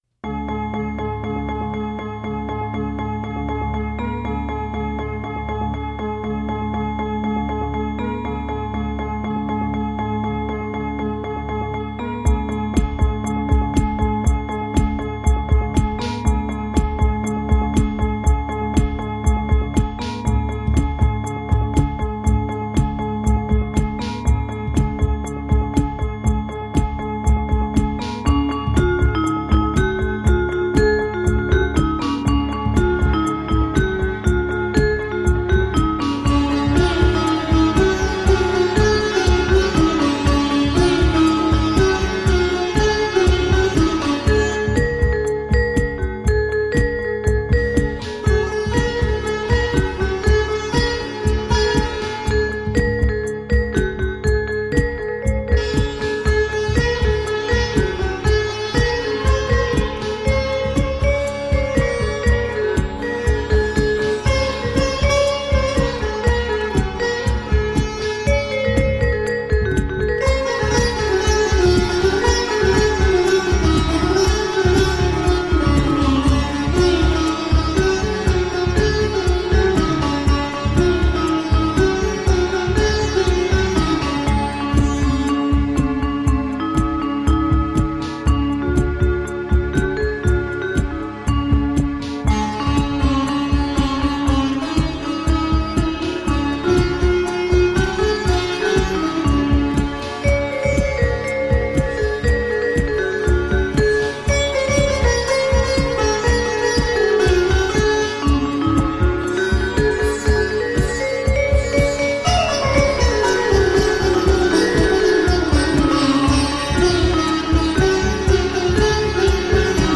Electro indian sitar.
Tagged as: World, Indian, Indian Influenced, Sitar